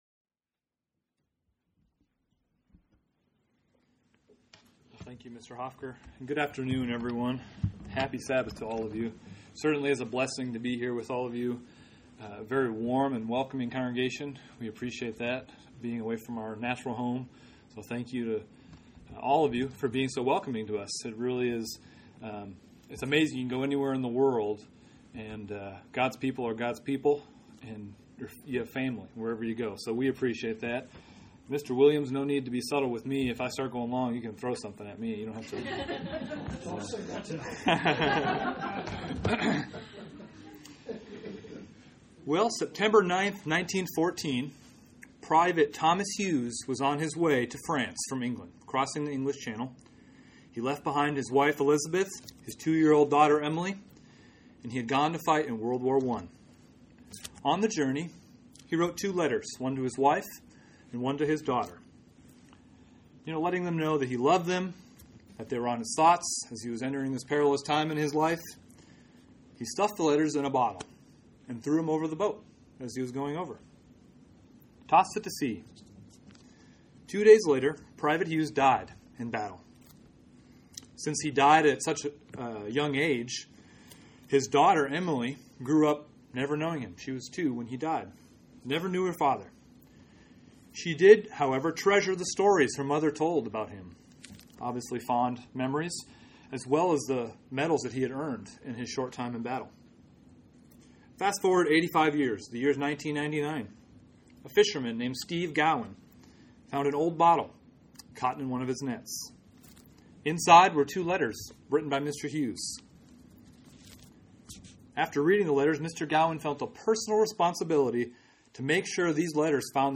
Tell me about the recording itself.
Given in Columbia, SC